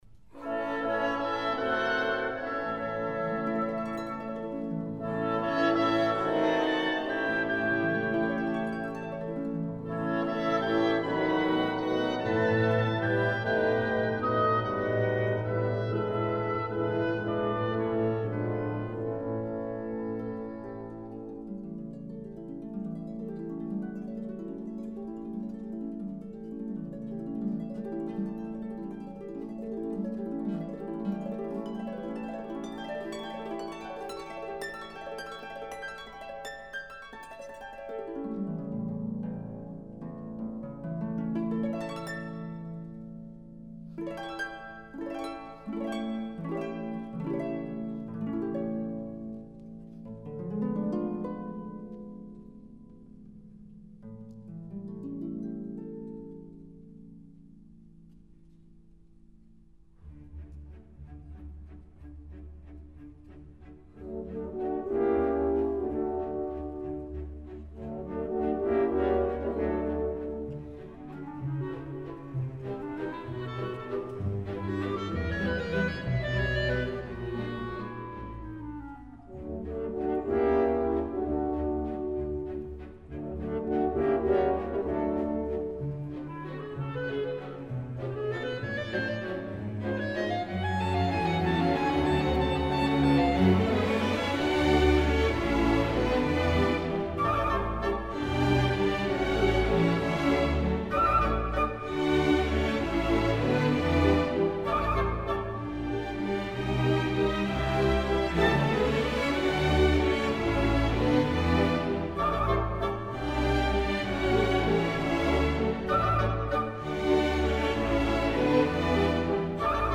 (Звучит фонограмма “Вальс Цветов” И.П. Чайковского).